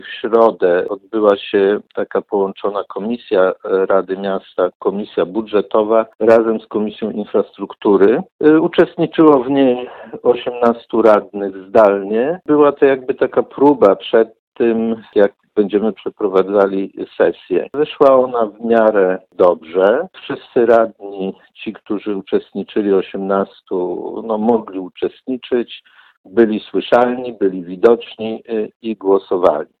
– System jest przygotowany, przetestowany, więc jesteśmy gotowi do zdalnego posiedzenia – tłumaczy Włodzimierz Szelążek – przewodniczący Rady Miasta Ełku.